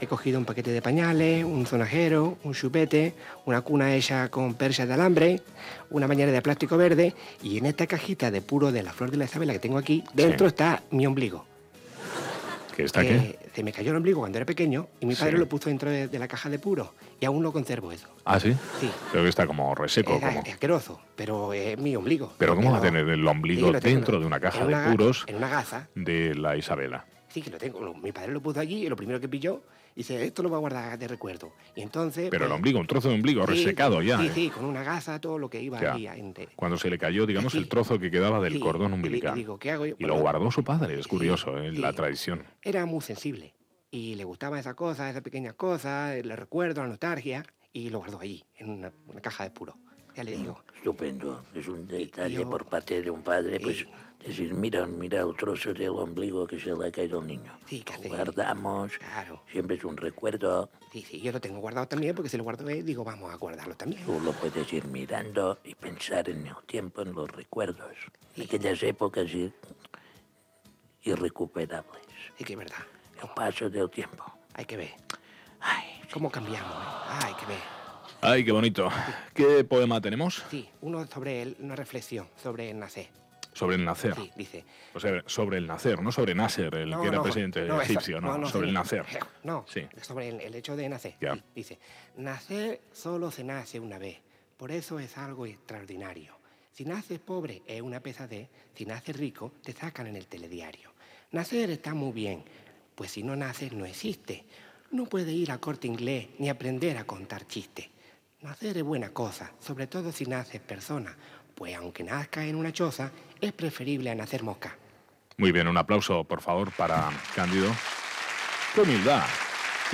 Secció humorística amb el senyor Casamajor i les imitacions de Toni Clapés.
Entreteniment